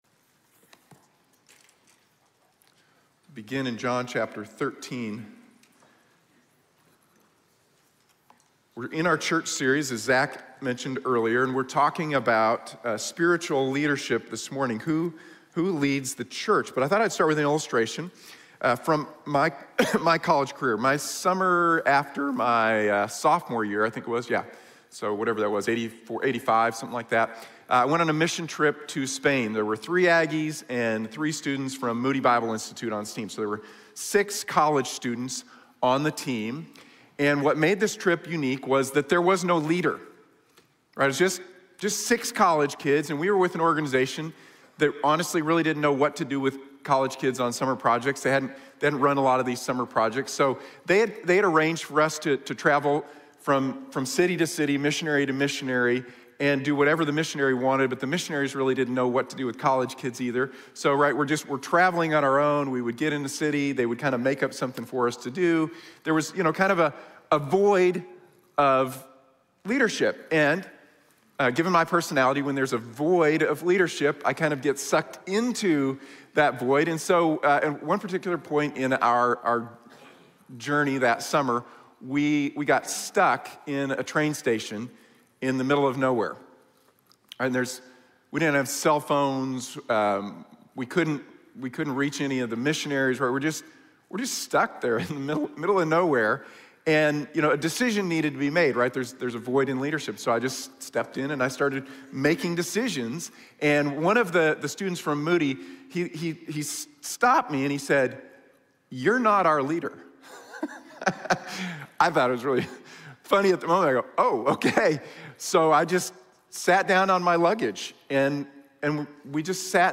¿Quién dirige la Iglesia? | Sermón de la Iglesia Bíblica de la Gracia